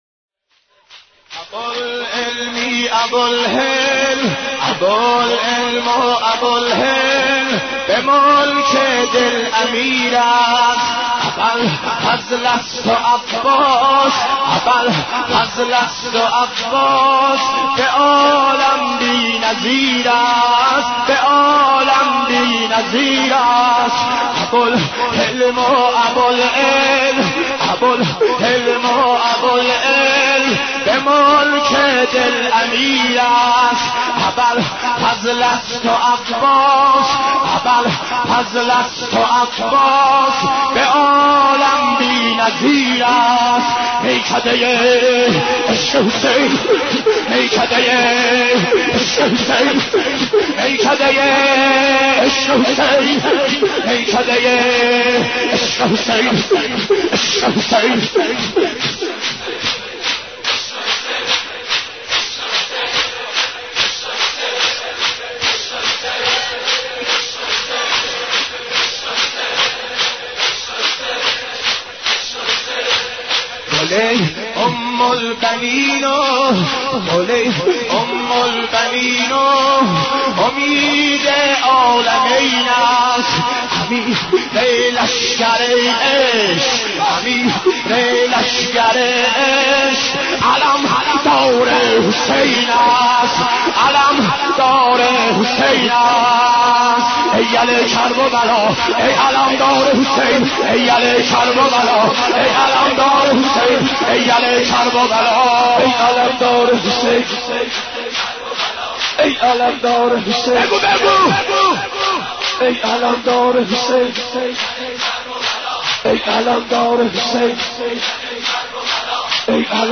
حضرت عباس ع ـ شور 10